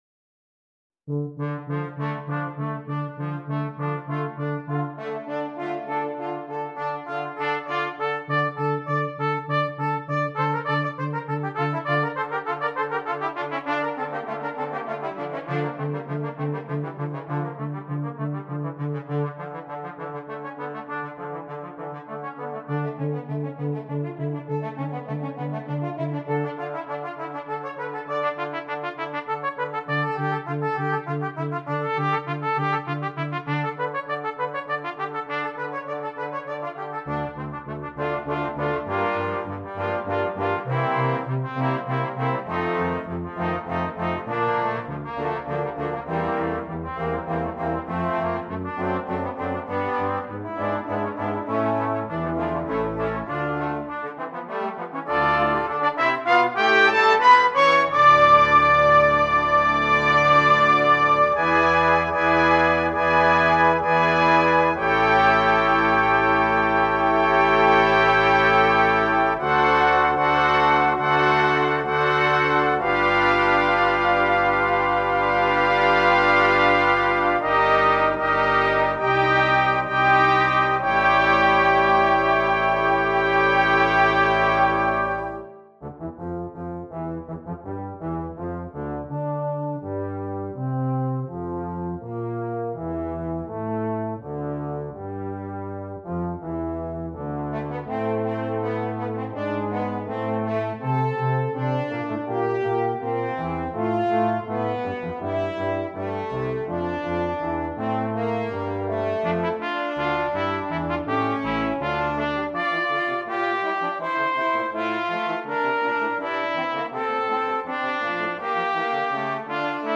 Brass Choir (4.2.2.1.1)
for 4 trumpets, 2 horns, 2 trombones, euphonium and tuba
The slow movement features the piccolo trumpet.